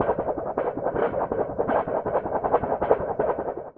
Viento.wav